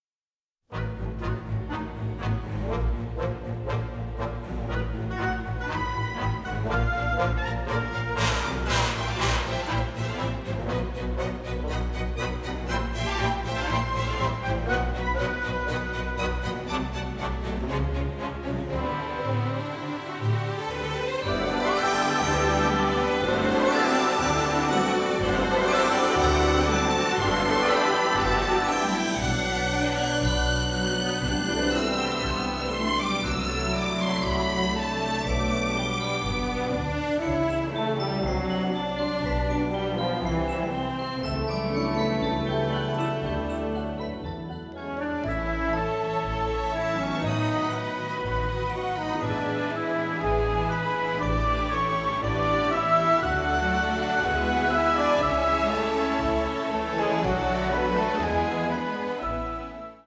Recorded at CTS Studios in London